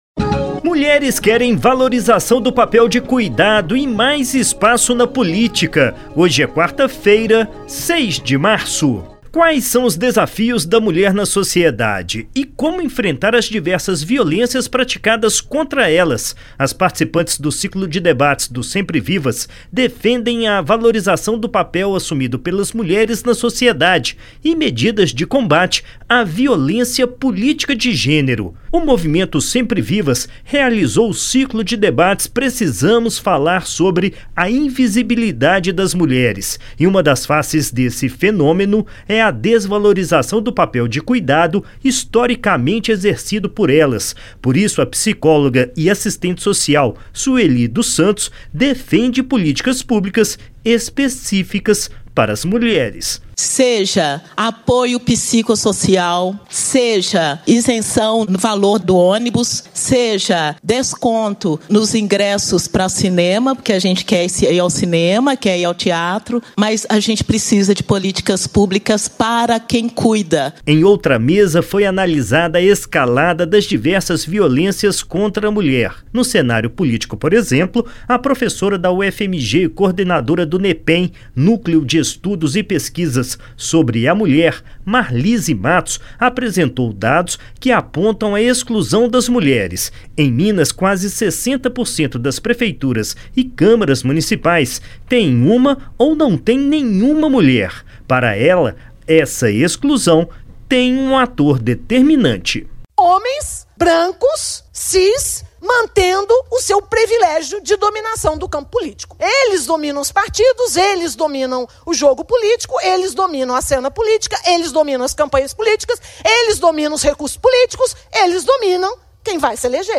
Boletim da ALMG – Edição n.º 5856